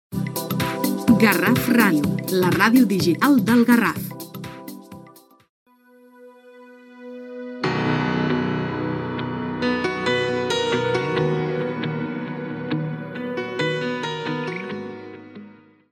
Musical
Identificació de l'emissora